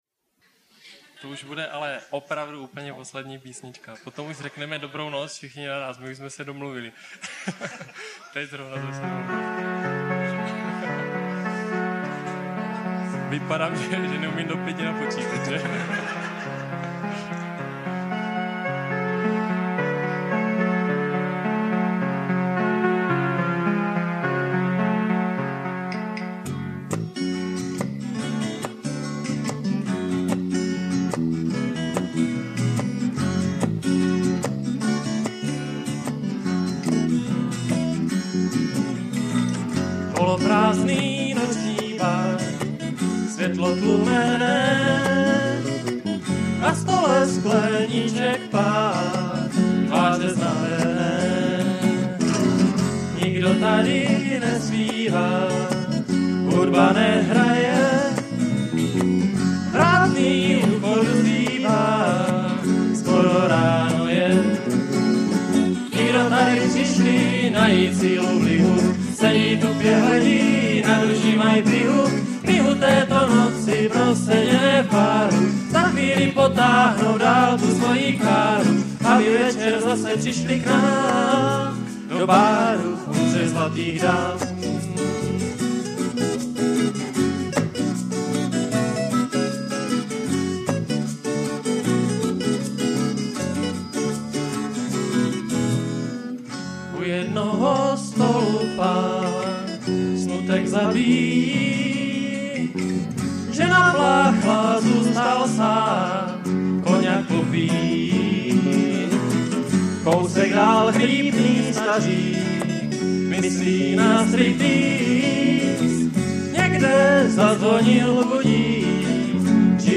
Po pravdě řečeno se mé tehdejší hudební preference pohybovaly po úplně jiné koleji - namátkou vybírám HNF, Visací zámek, taky Iron Maiden a pomalu jsem začínal být i Led Zeppelin pozitivní, takže ze začátku jsem se přes ryze akusticky folkový zvuk přenášel docela špatně.
hrdinný tenor, trampská kytara
klavír z umělé hmoty